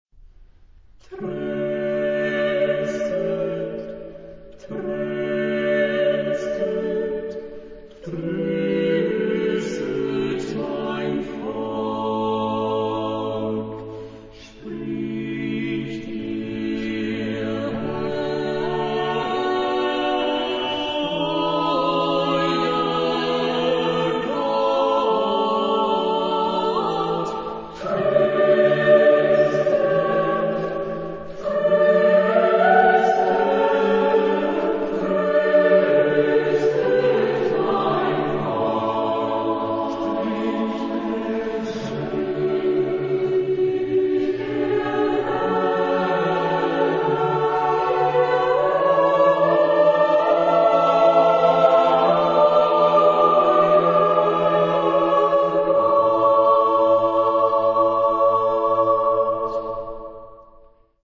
Genre-Style-Form: Motet ; Sacred
Type of Choir: SSAATBB  (7 mixed voices )
Tonality: D major